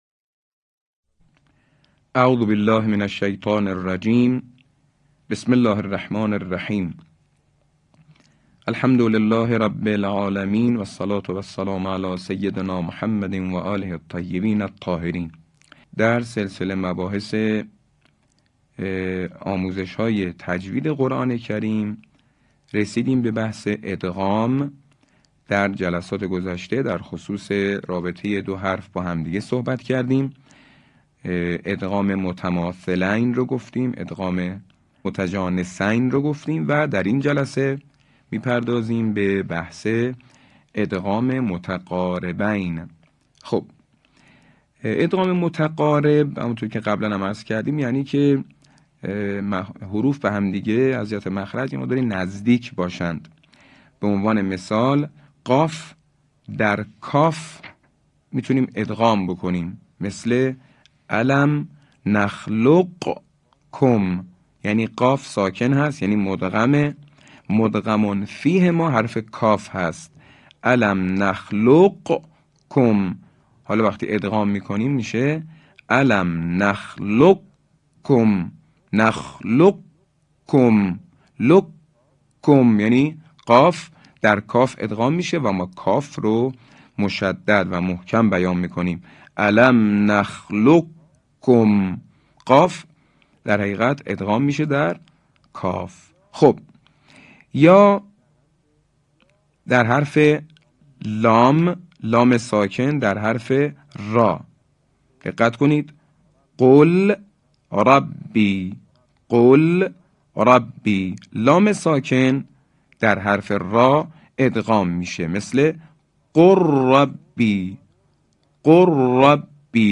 آموزش قرآن کریم